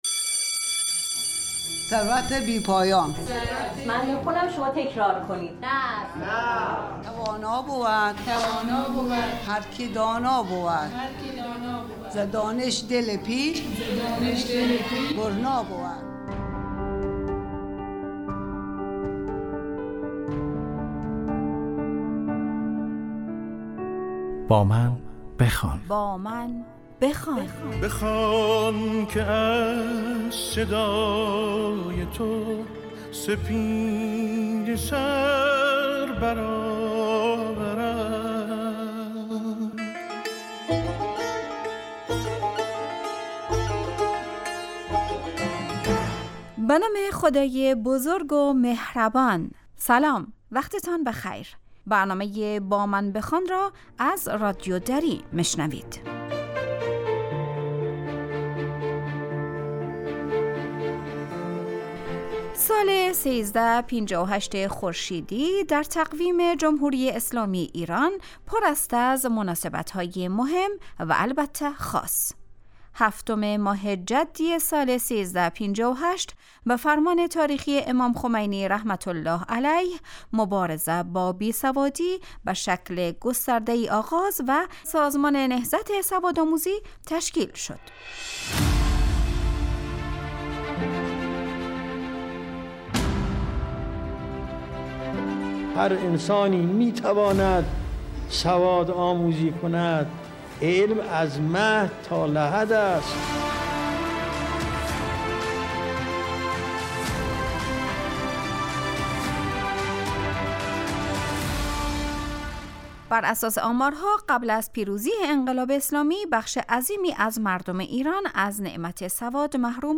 این برنامه به یکی از دستاوردهای مهم انقلاب اسلامی ایران یعنی تشکیل نهضت سوادآموزی و آثار فرامرزی آن در حوزه افغانستان می پردازد . مصاحبه با سوادآموزان افغانستانی در ایران و یک معلم افغانستانی و همچنین یکی از فرهیختگان افغانستانی که تحصیلات خود را از نهضت سوادآموزی در ایران شروع کرد.